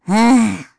Pansirone-Vox-Deny_kr.wav